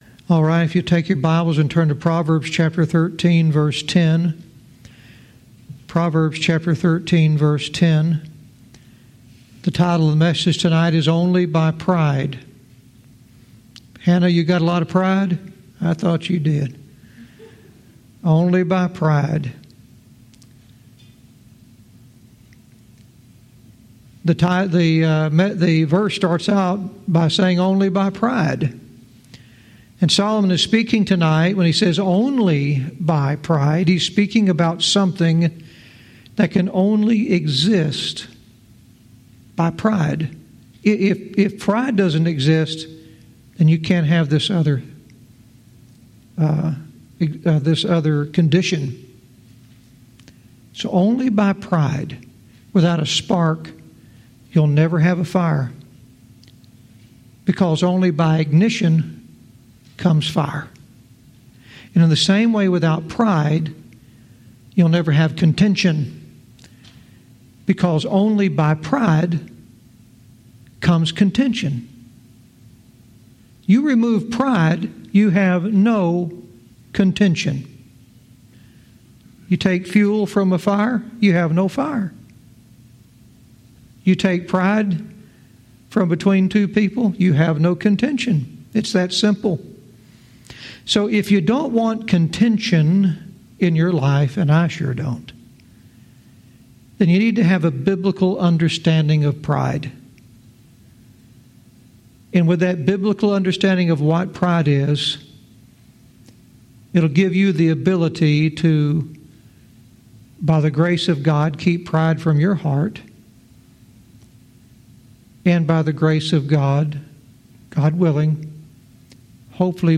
Verse by verse teaching - Proverbs 13:10 "Only by Pride"